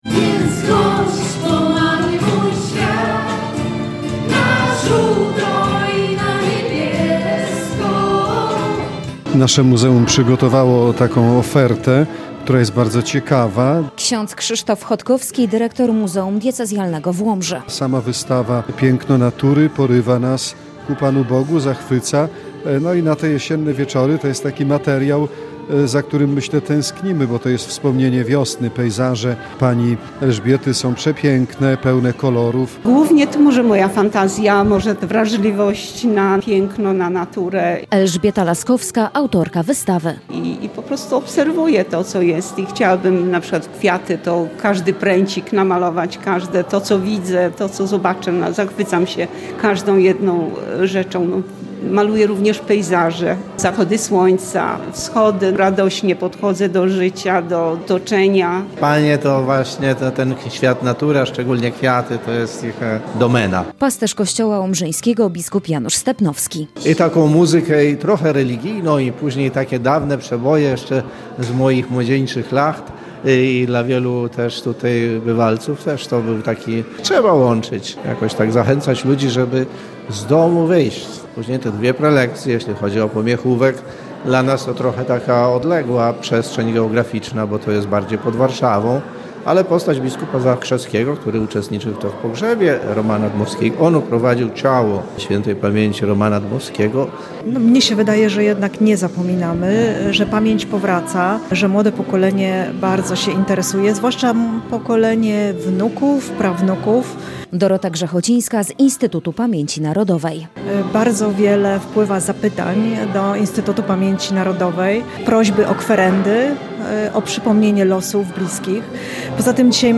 W piątkowy wieczór sale Muzeum Diecezjalnego w Łomży wypełniły się wielbicielami sztuki. Wernisażem wystawy „Piękno natury” zainaugurowano Jesień Kulturalną.